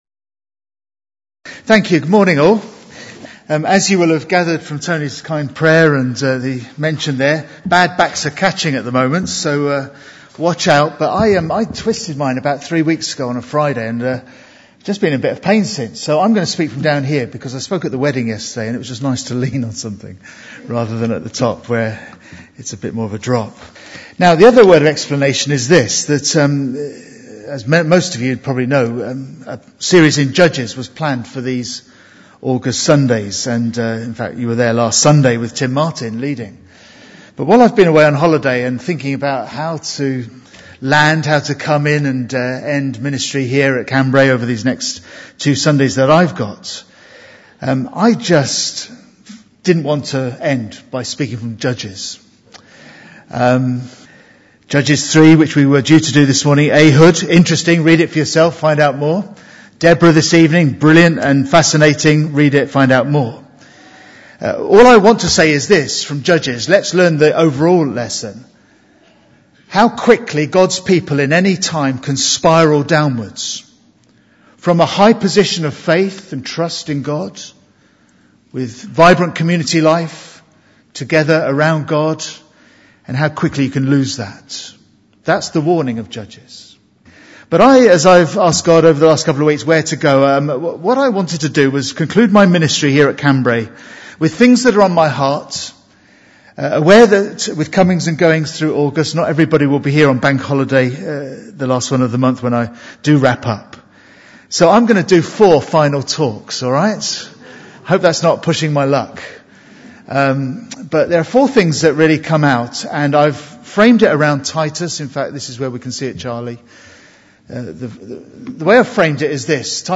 2016 Service type: Sunday AM Bible Text